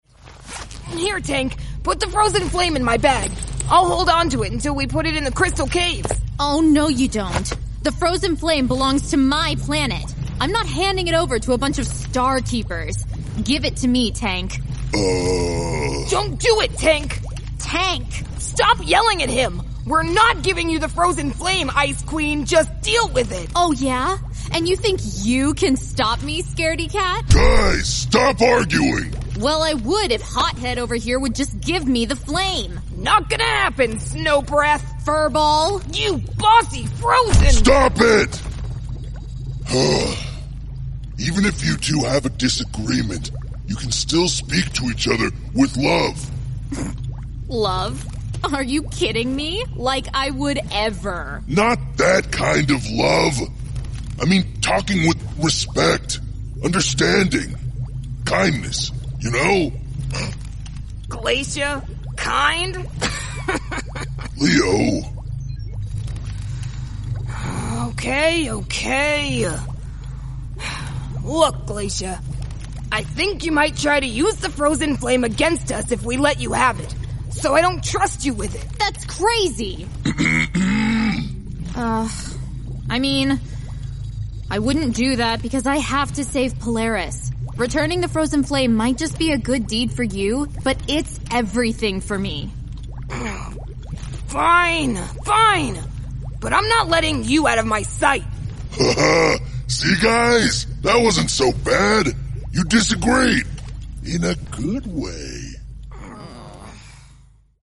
In the last episode of “Out Of The Shadows,” Glacia and Leo were arguing a LOT.